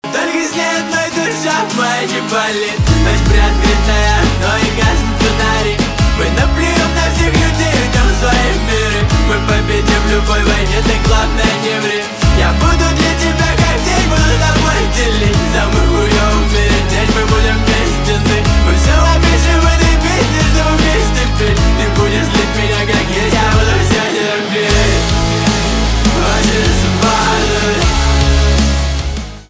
русский рок , гитара , барабаны